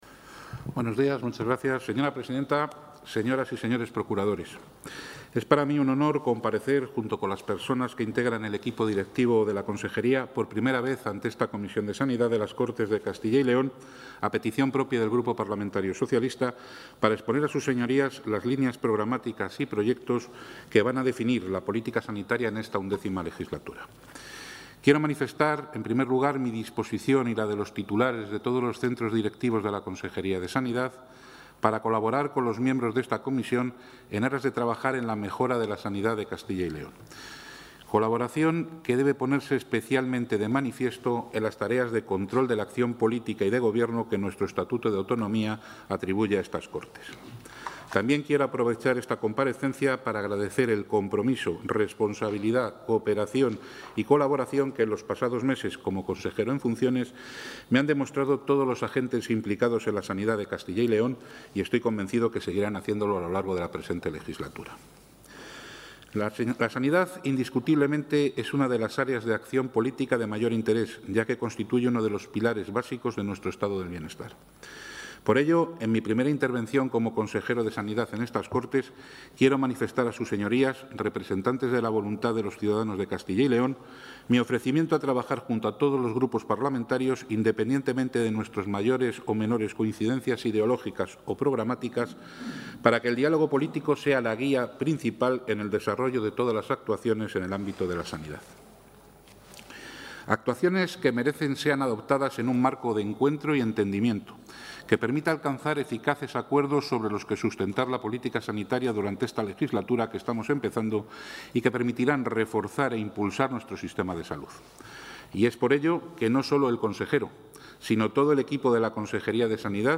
Intervención del consejero de Sanidad.
El consejero de Sanidad, Alejandro Vázquez Ramos, ha comparecido ante la Comisión de Sanidad de las Cortes de Castilla y León para exponer los proyectos y líneas esenciales que su departamento va a desarrollar a lo largo de la XI Legislatura. Vázquez ha planteado como objetivo institucional alcanzar un gran pacto por la sanidad pública, basado en el debate y el acuerdo social y que garantice, en el presente y el futuro, la función primordial del sistema público de salud: asegurar el derecho a la protección y promoción de la salud de toda la población castellana y leonesa.